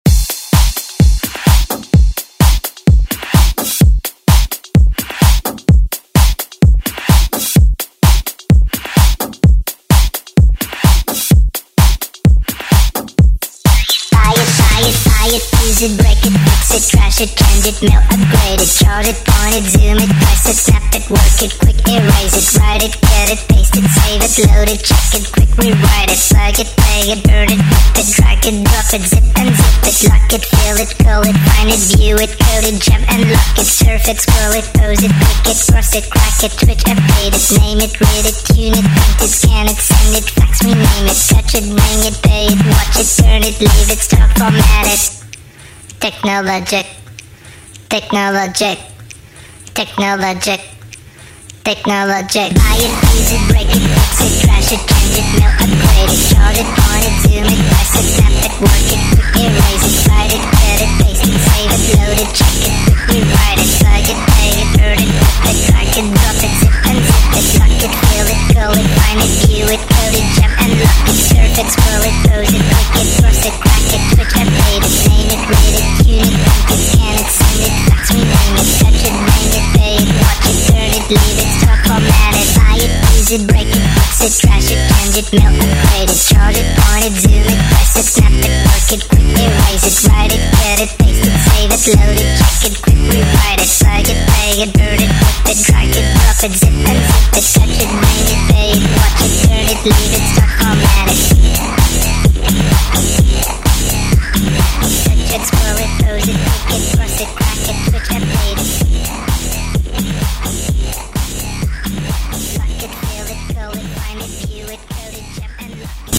Electronic Euro House Music
Genre: 90's
BPM: 130